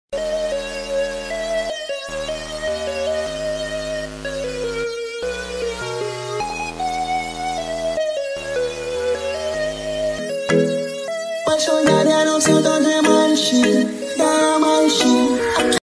• Качество: 321 kbps, Stereo